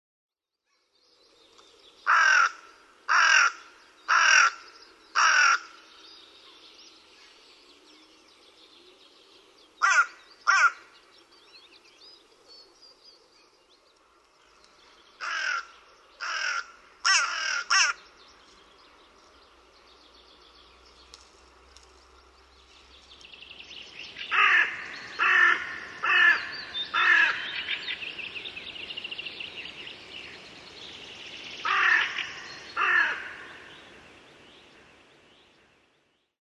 Varis
Kuuntele variksen ääntä.